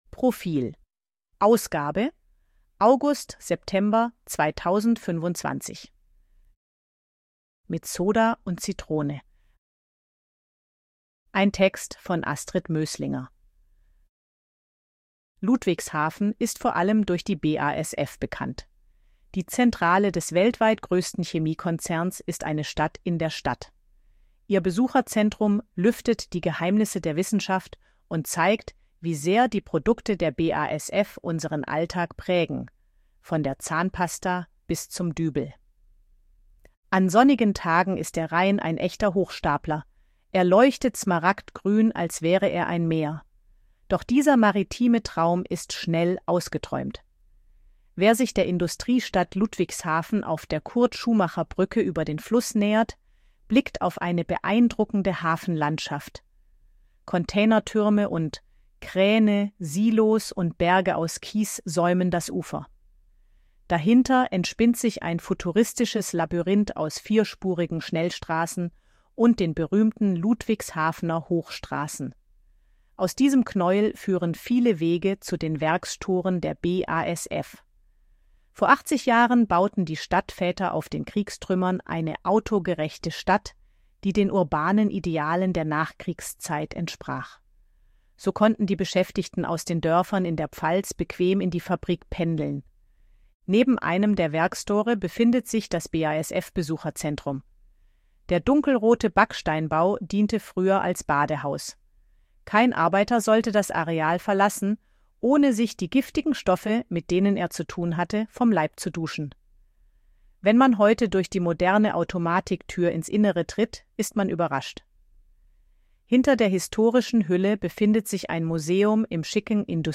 Artikel von KI vorlesen lassen
ElevenLabs_252_KI_Stimme_Frau_Betriebsausflug_v2.ogg